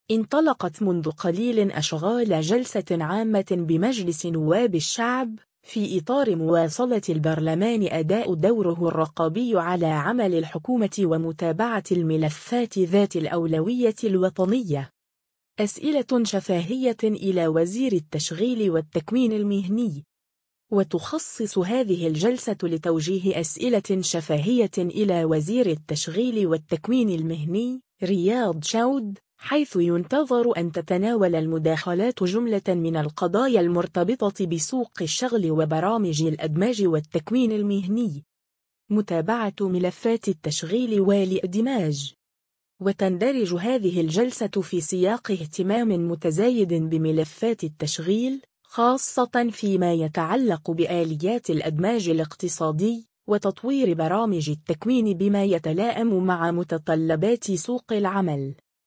انطلقت منذ قليل أشغال جلسة عامة بمجلس نواب الشعب، في إطار مواصلة البرلمان أداء دوره الرقابي على عمل الحكومة ومتابعة الملفات ذات الأولوية الوطنية.
أسئلة شفاهية إلى وزير التشغيل والتكوين المهني
وتُخصص هذه الجلسة لتوجيه أسئلة شفاهية إلى وزير التشغيل والتكوين المهني، رياض شوّد، حيث ينتظر أن تتناول المداخلات جملة من القضايا المرتبطة بسوق الشغل وبرامج الإدماج والتكوين المهني.